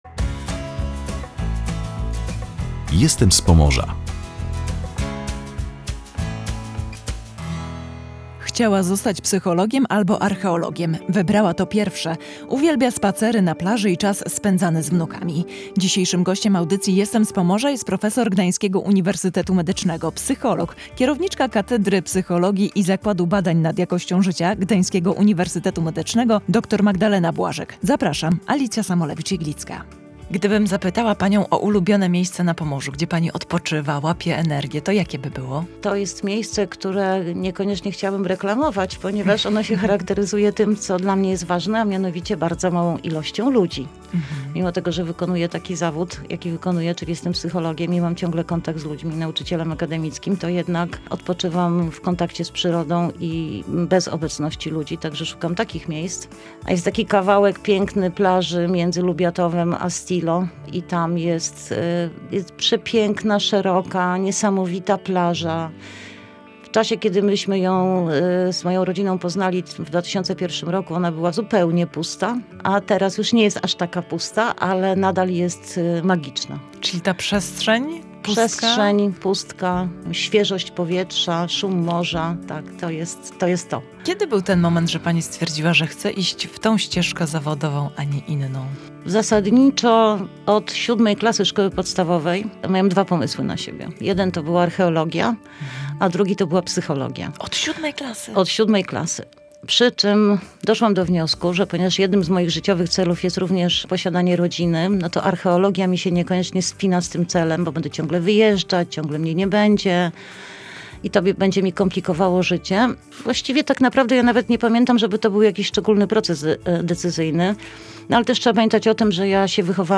Radio Gdańsk